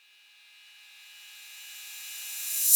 808CY_Rev.wav